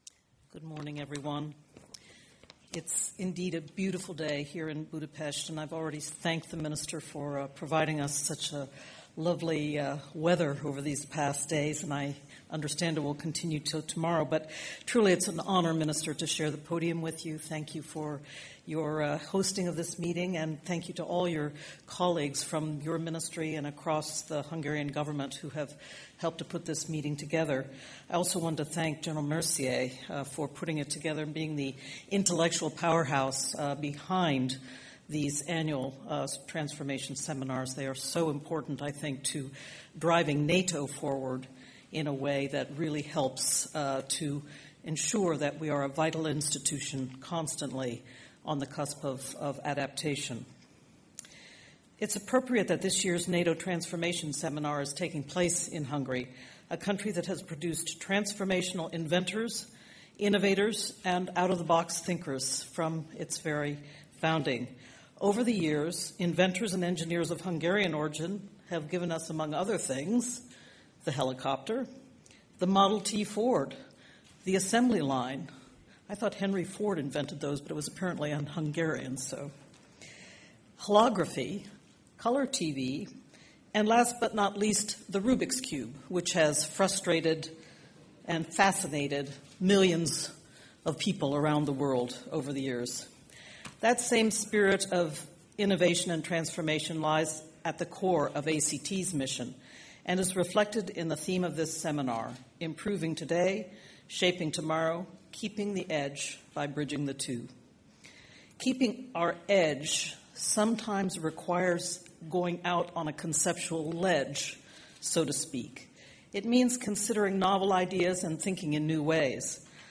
Remarks by NATO Deputy Secretary General Rose Gottemoeller at the opening session of the NATO Transformation Seminar in Budapest
(As delivered)